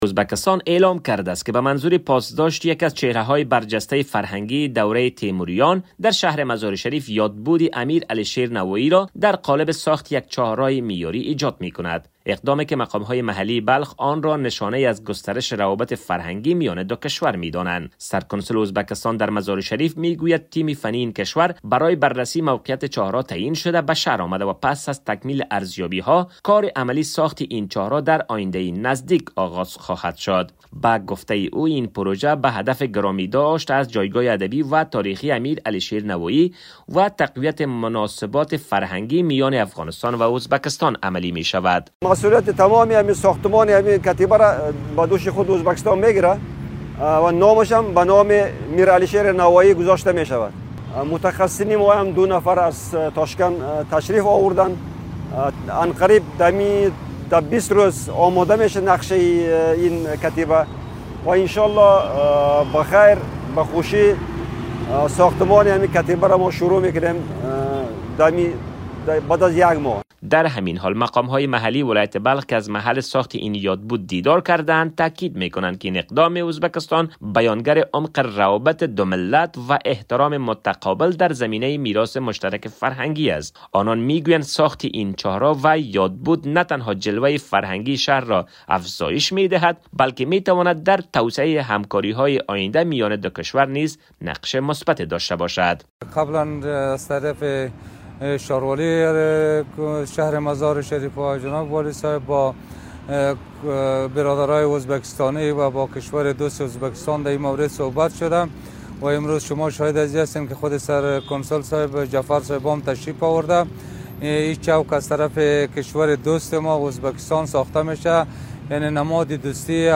خبر / افغانستان